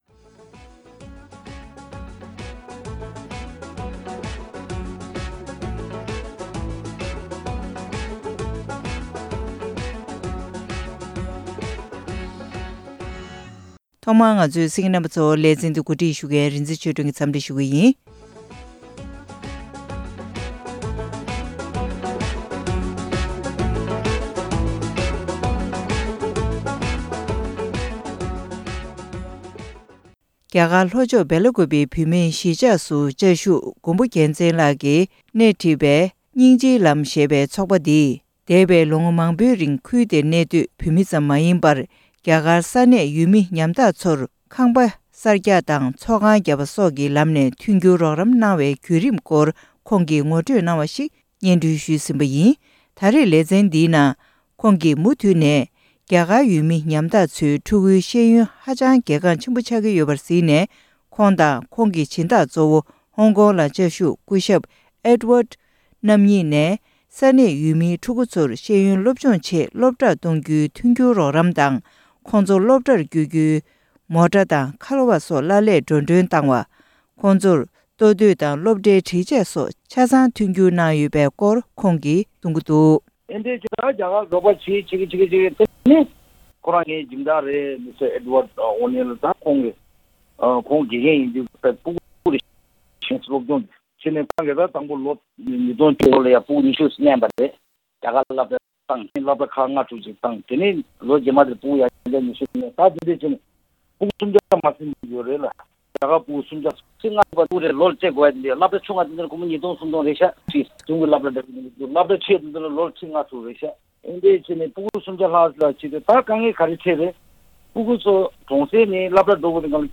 སྙིང་རྗེའི་ལམ་ཐེབས་རྩ་ཞེས་པའི་ཚོགས་པའི་བྱུང་རིམ་དང་དུམ་བུ་གཉིས་པ། སྒྲ་ལྡན་གསར་འགྱུར།